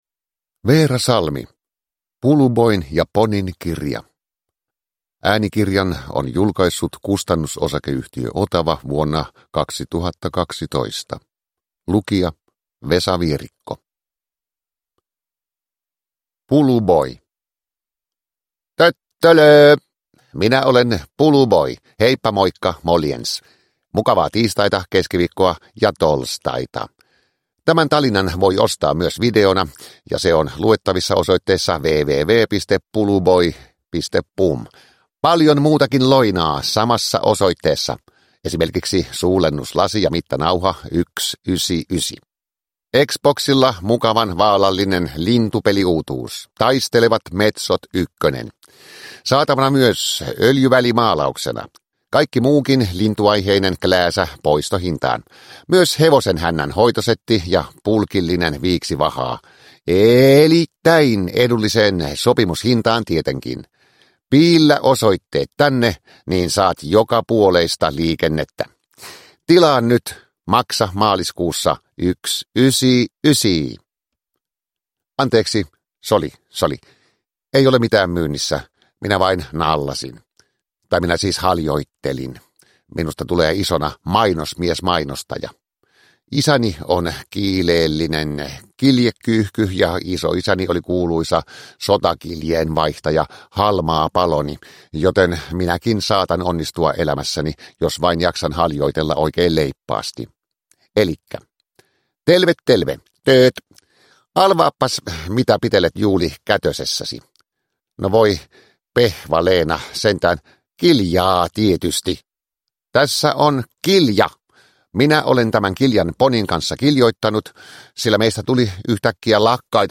Puluboin ja Ponin kirja – Ljudbok – Laddas ner
Uppläsare: Vesa Vierikko